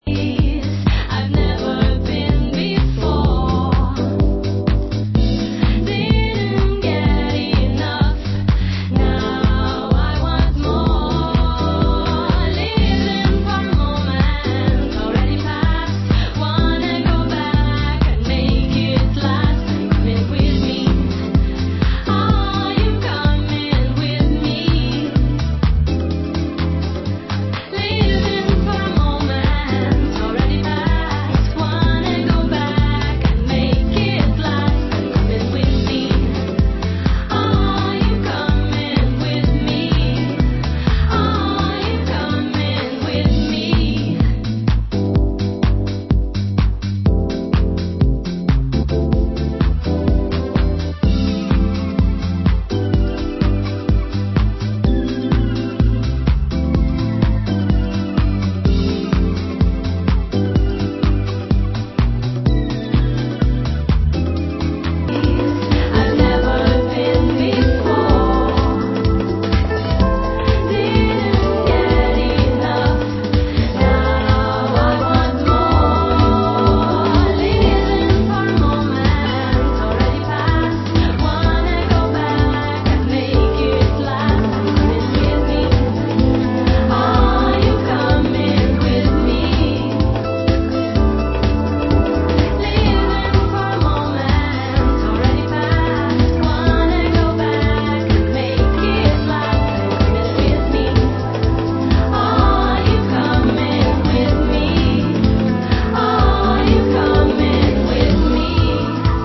Genre: Down Tempo